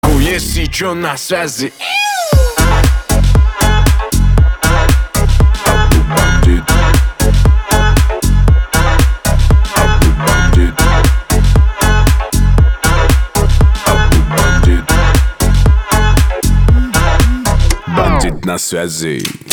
• Качество: 320, Stereo
громкие
веселые